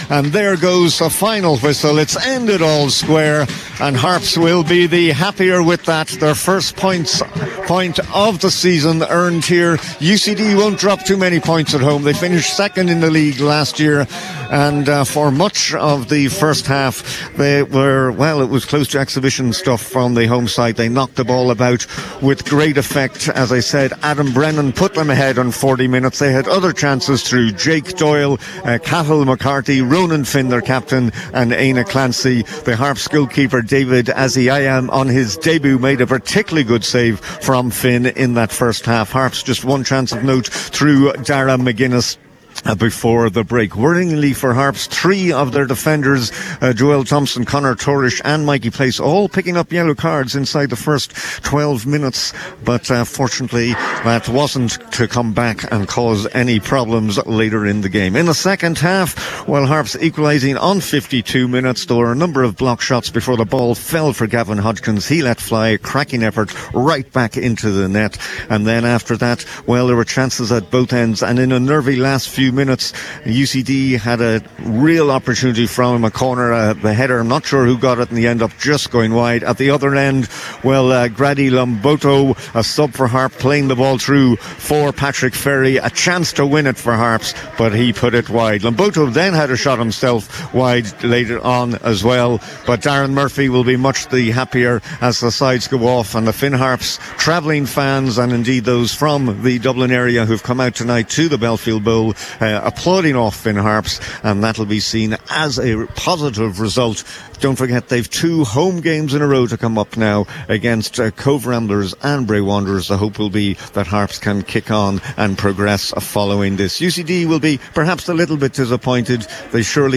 reported live at full time…